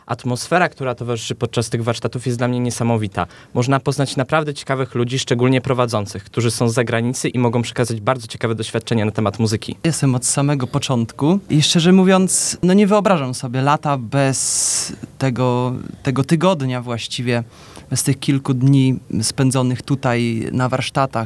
Panowie o gospel.mp3